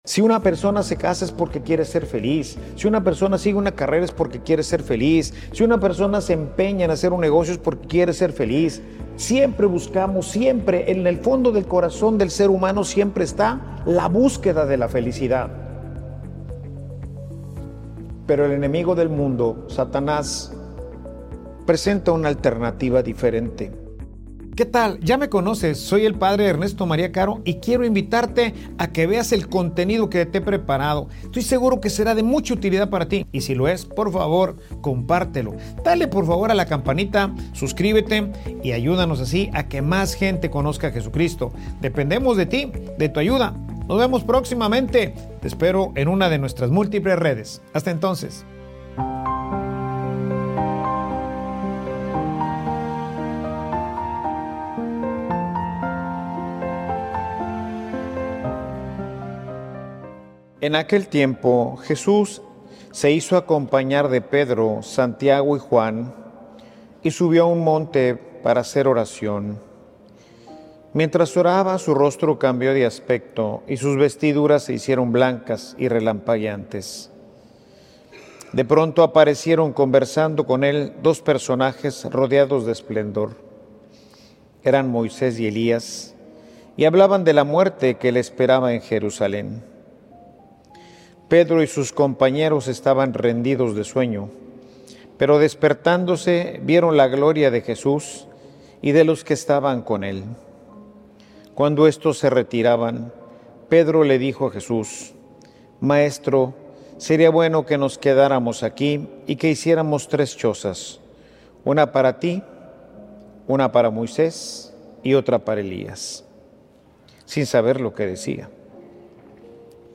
Homilia_Vives_para_Dios_o_para_el_mundo.mp3